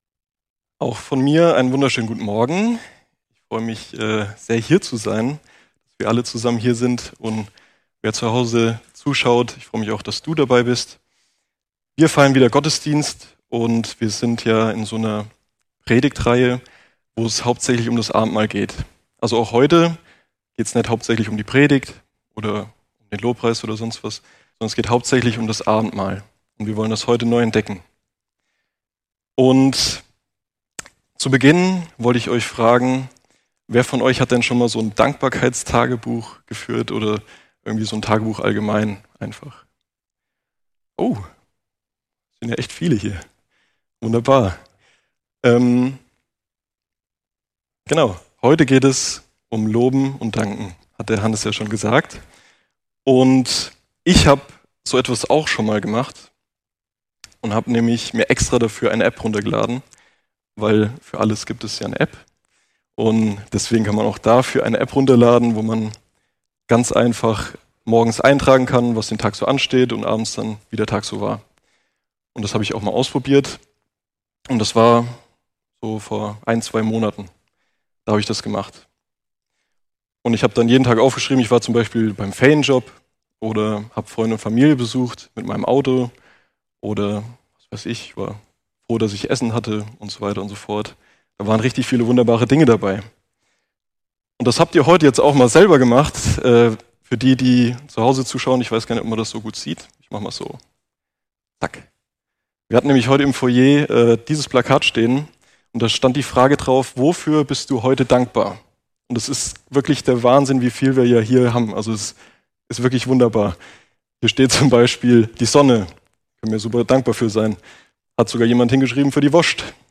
Gottesdienst - Loben und Danken ~ FeG Herborn Podcasts Podcast
Predigen und andere Vorträge